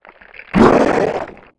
attack_act_2.wav